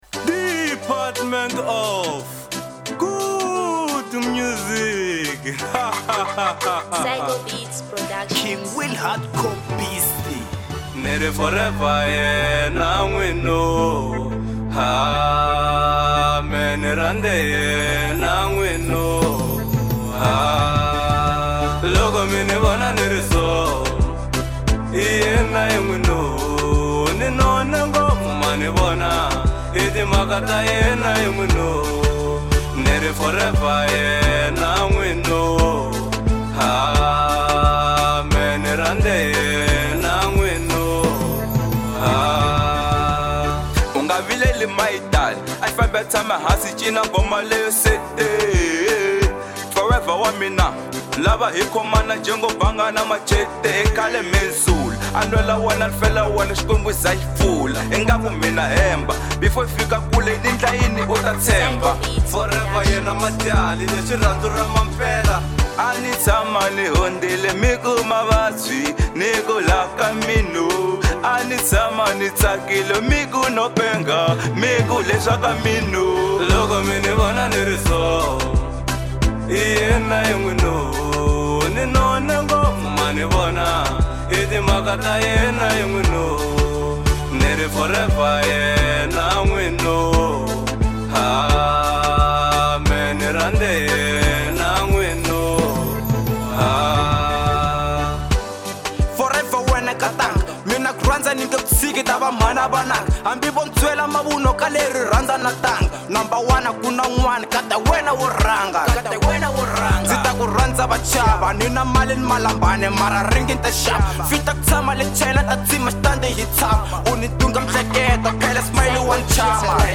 Genre : Afro Pop